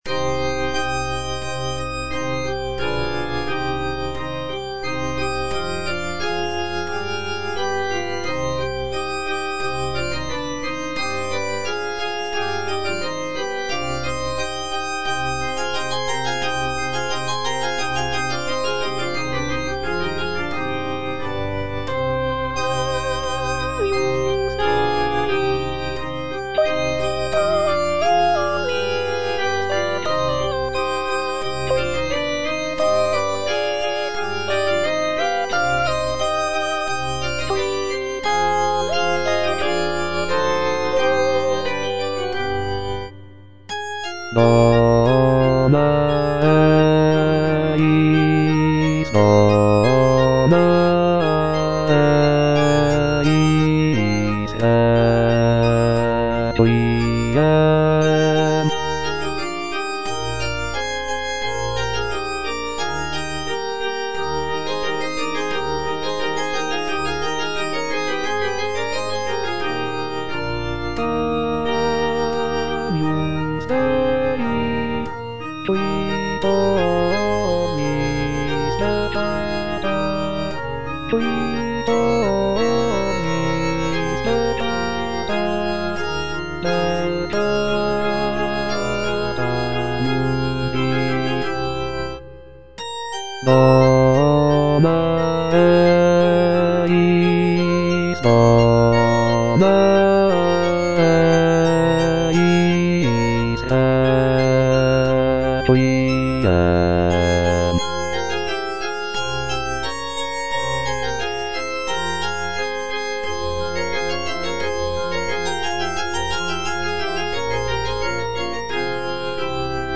M. HAYDN - REQUIEM IN C (MISSA PRO DEFUNCTO ARCHIEPISCOPO SIGISMUNDO) MH155 Agnus Dei - Bass (Voice with metronome) Ads stop: auto-stop
The work is characterized by its somber and mournful tone, reflecting the solemnity of a funeral mass. Featuring beautiful choral harmonies and expressive melodies, Haydn's Requiem in C showcases his mastery of sacred music and ability to evoke deep emotional responses through his compositions.